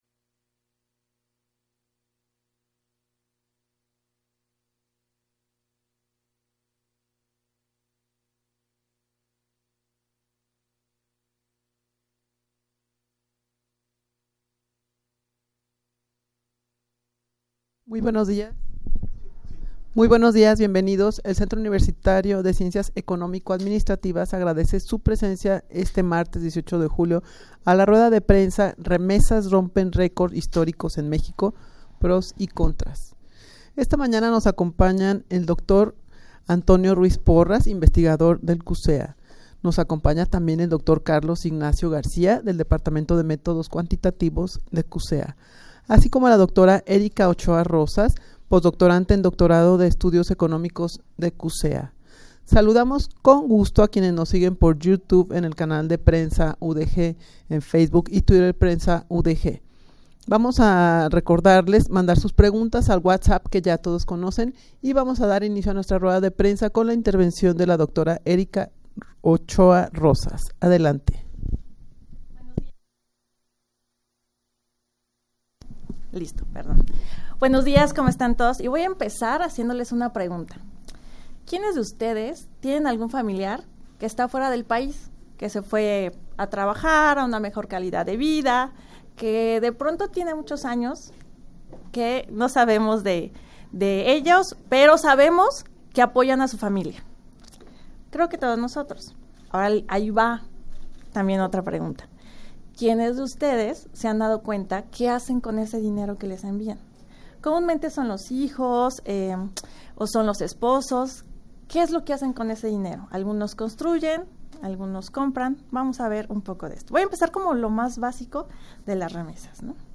Audio de la Rueda de Prensa
rueda-de-prensa-las-remesas-rompen-record-historicos-en-mexico-pros-y-contras.mp3